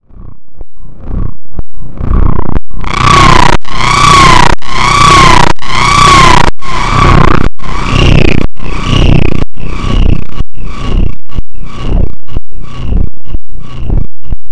I accidentally synthesized angry seagulls the other day on the moog model 15 app:
That’s the stuff of nightmares.